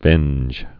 (vĕnj)